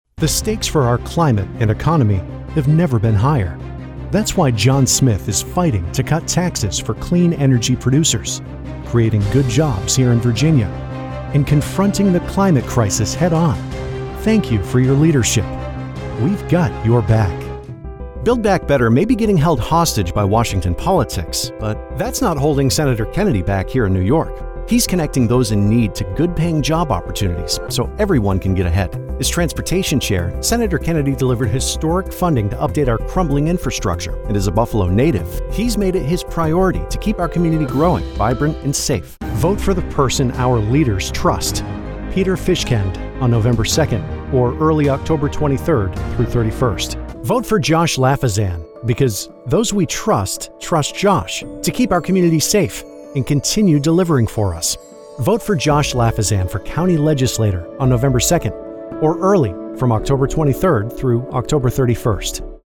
Male
English (North American)
Adult (30-50)
For elearning, corporate narration, and IVR, I deliver a professional, authoritative, confident sounding voiceover.
Political Spots
Political Demo
All our voice actors have professional broadcast quality recording studios.
0119Political_Demo.mp3